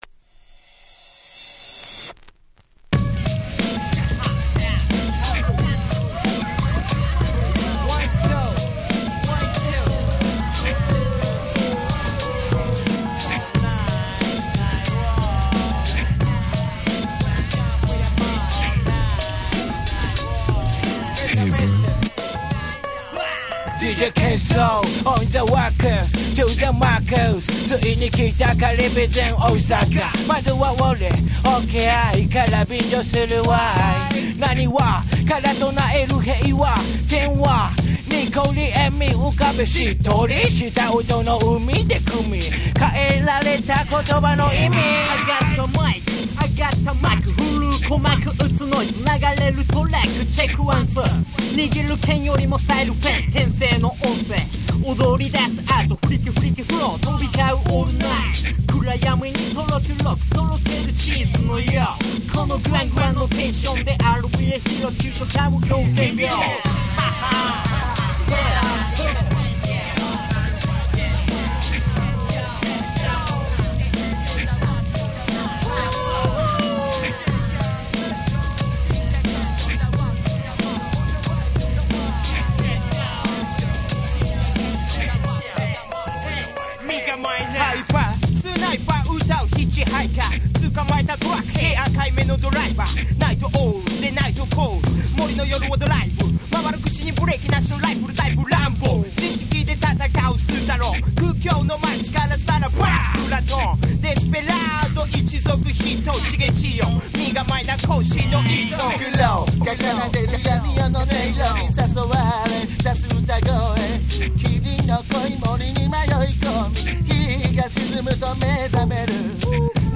97' Japanese Hip Hop Classic !!
スリリングな展開のマイクリレーは今聴いてもガンガンに首が動いてしまいます。